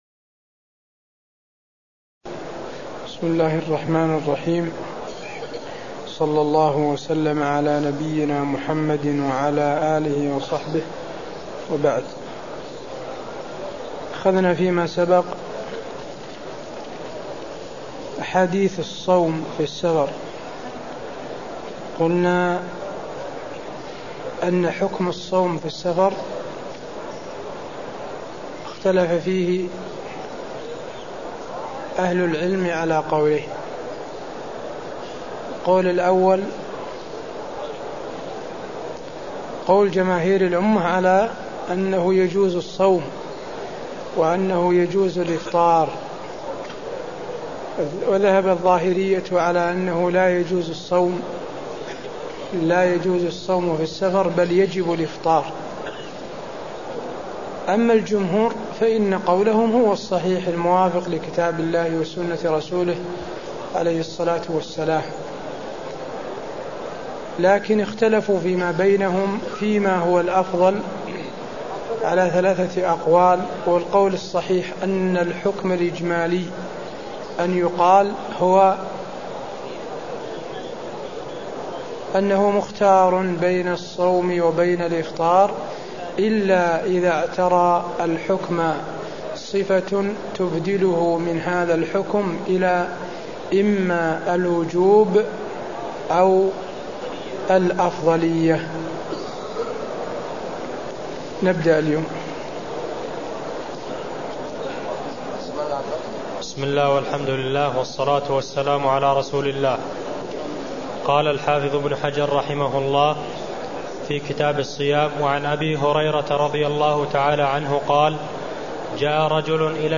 المكان: المسجد النبوي الشيخ: فضيلة الشيخ د. حسين بن عبدالعزيز آل الشيخ فضيلة الشيخ د. حسين بن عبدالعزيز آل الشيخ أحكام الشيخ الكبير والمرأة الكبيرة في الصوم (007)